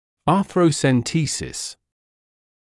[ˌɑːθrəusən’tiːsəs][ˌаːсроусэн’тиːсэс]артроцентез